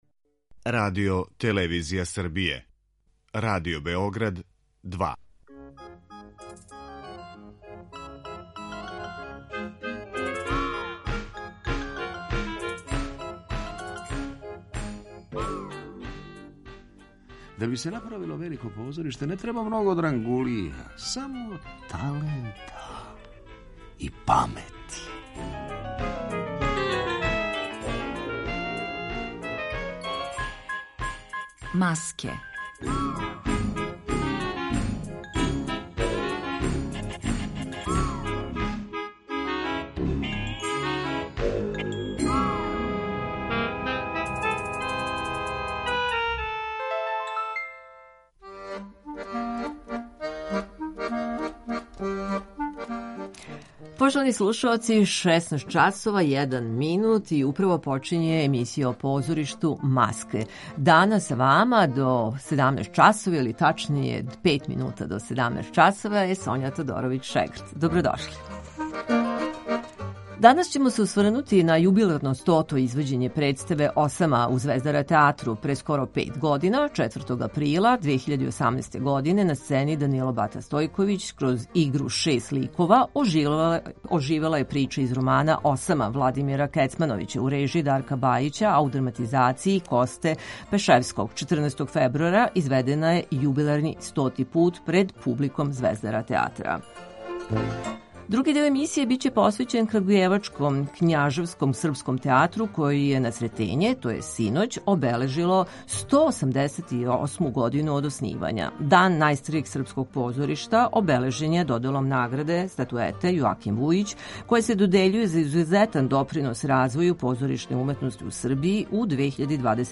а на јубиларном извођењу снимили смо и остале ауторе. Други део емисије биће посвећен Крагујевачком Књажевско-српском театару који је на Сретење, 15. фебруара, обележило 188 година од оснивања.